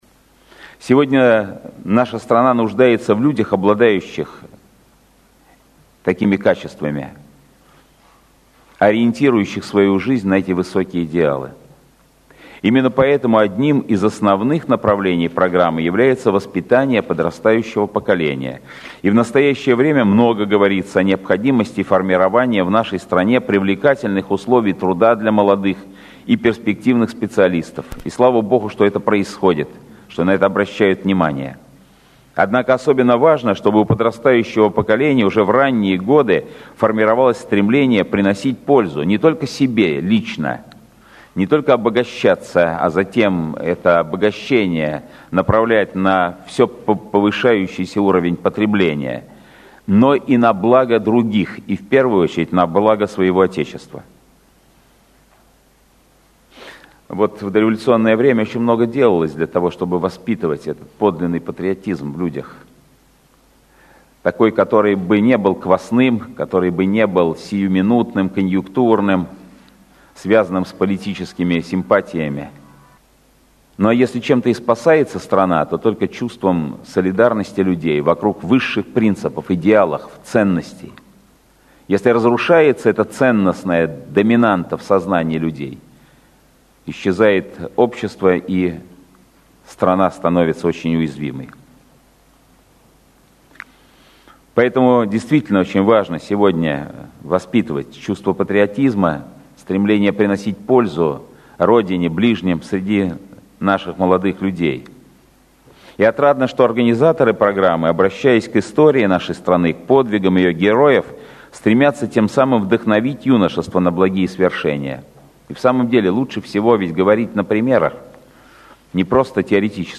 Слово Патриарха Кирилла на заседании Попечительского совета программы «Александр Невский» Фонда Андрея Первозванного и Центра национальной славы, а также программы «Александр Невский — имя России».